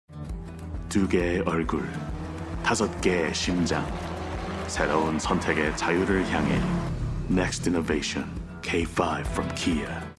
外籍韩语男5-抒情
外籍韩语男5-KIA起亚汽车.mp3